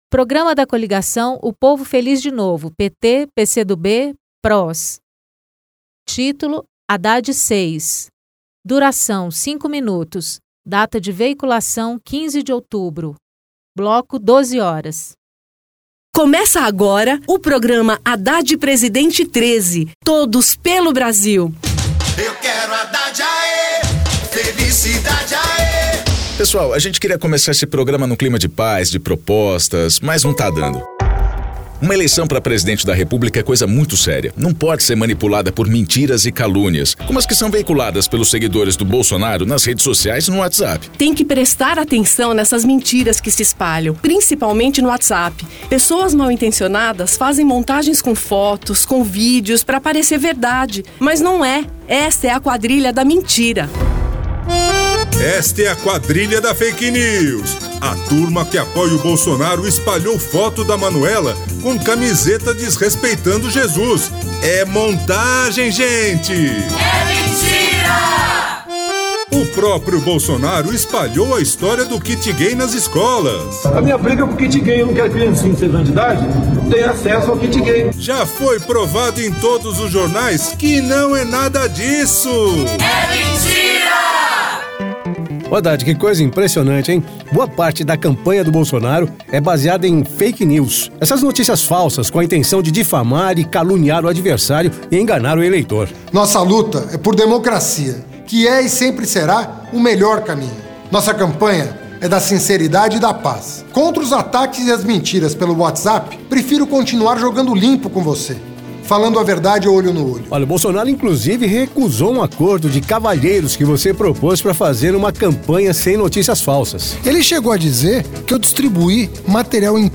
Gênero documentaldocumento sonoro
Entrevista : temático